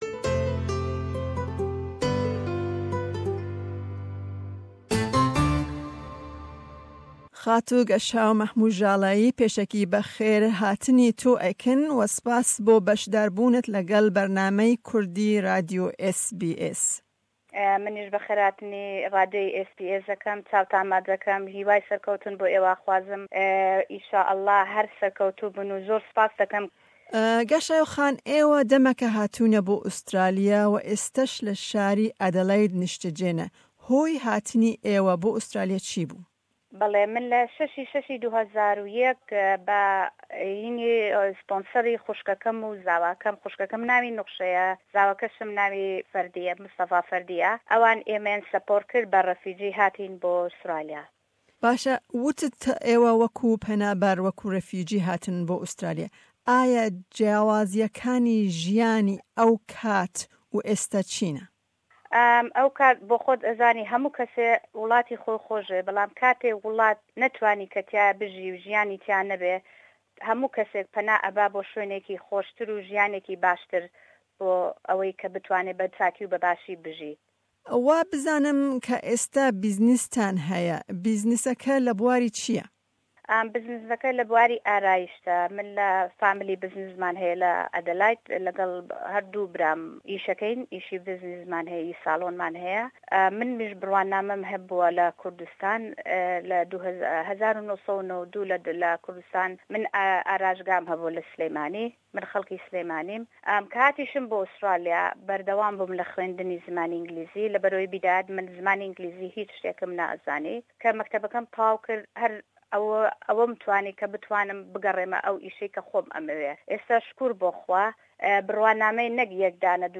hevpeyvîne